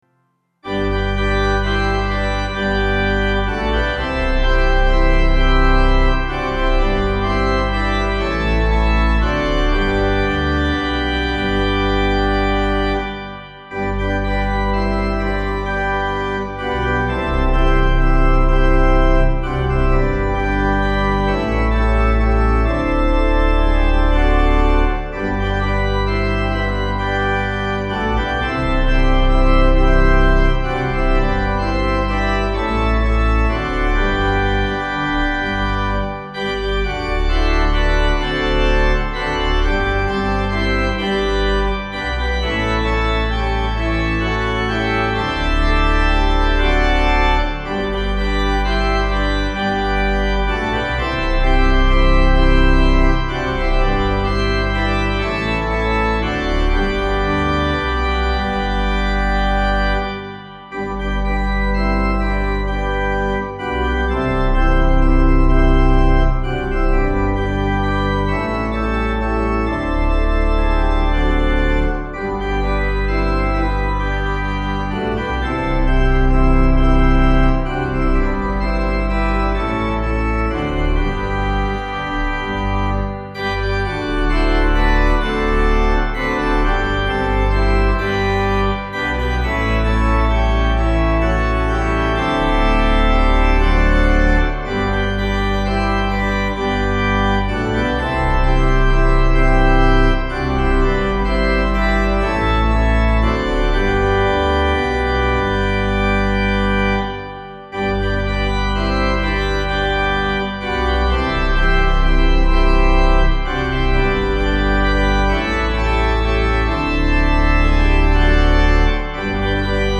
(CM)   3/G